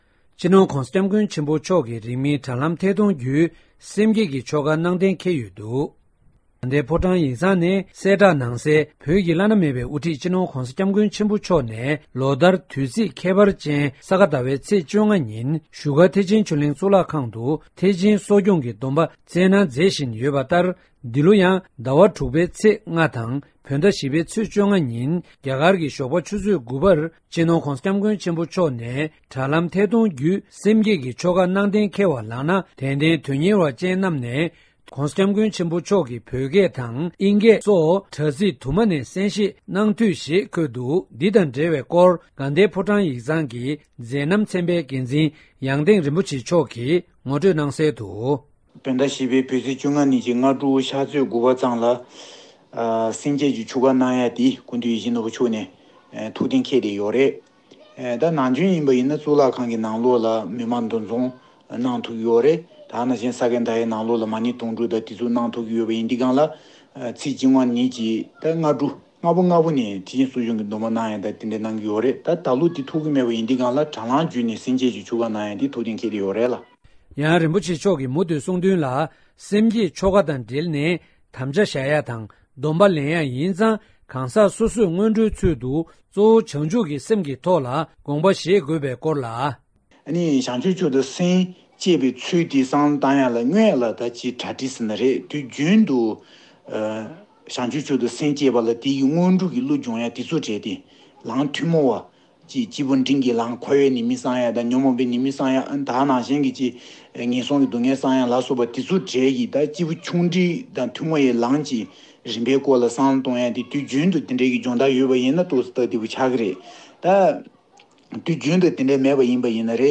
ས་གནས་ནས་བཏང་བའི་གནས་ཚུལ་ལ་གསན་རོགས།།